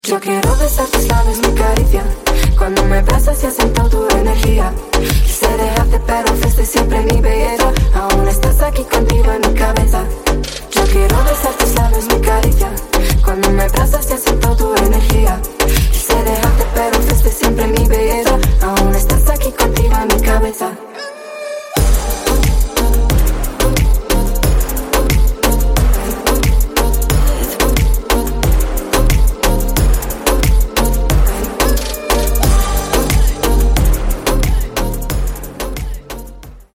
Латинские Рингтоны
Рингтоны Ремиксы » # Танцевальные Рингтоны